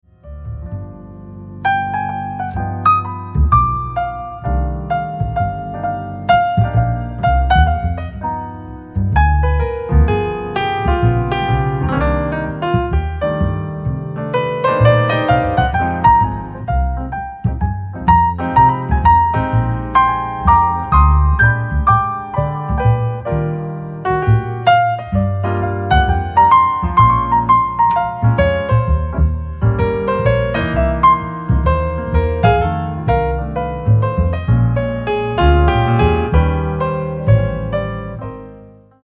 PIANO TRIO
甘く儚く、切なげに舞い上がるミラクルなメロディの応酬。